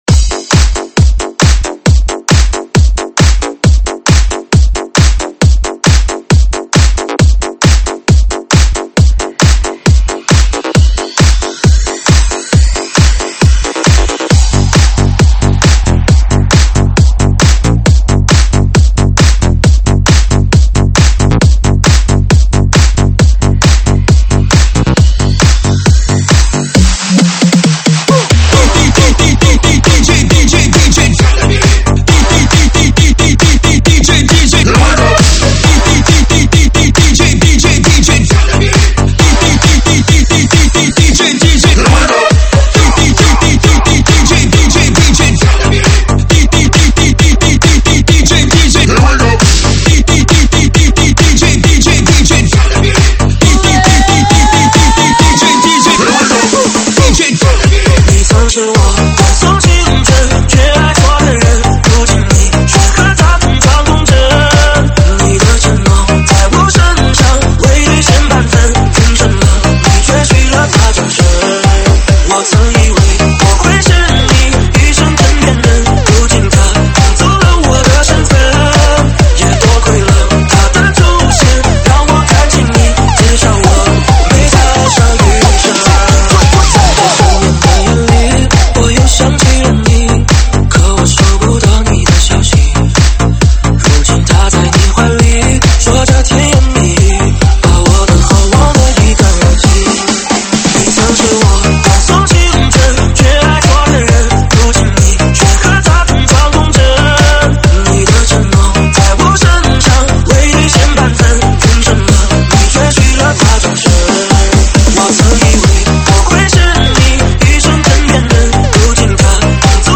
舞曲类别：电子Electro